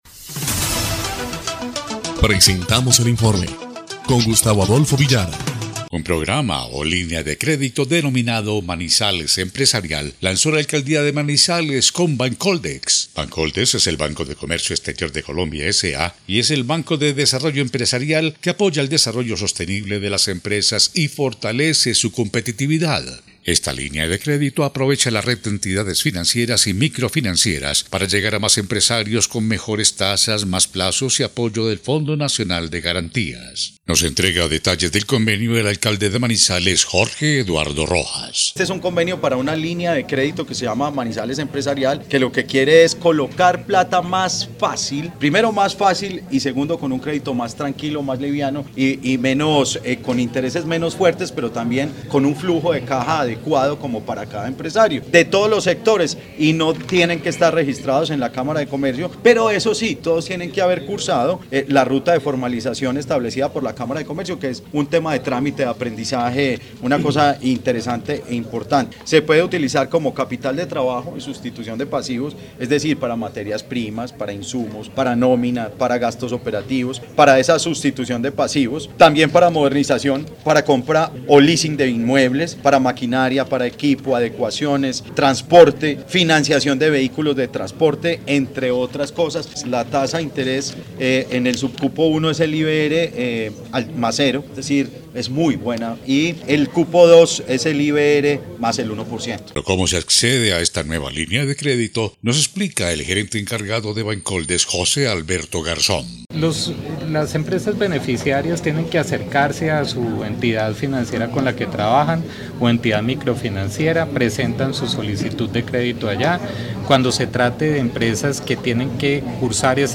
Alcalde de Manizales – Presidente encargado de Bancoldex